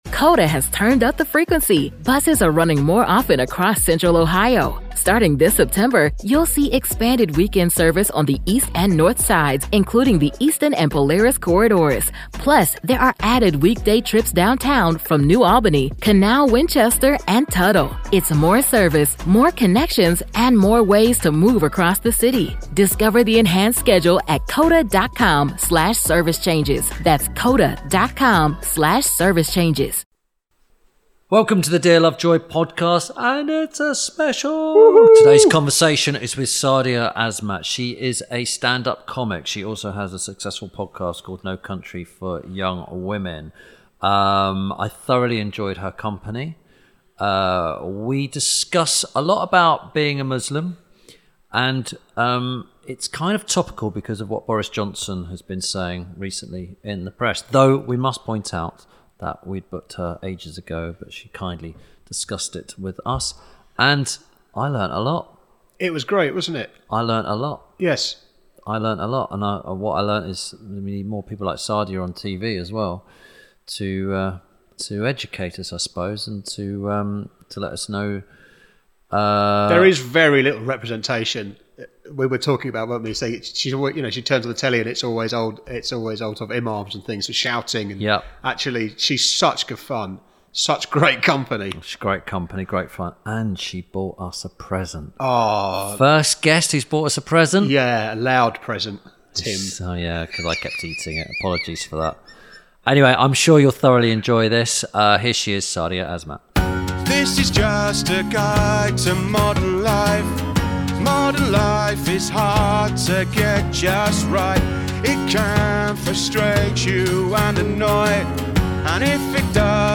This week Tim Lovejoy talks to comedian Sadia Azmat. Tim and Sadia discuss comedy, faith and Boris Johnson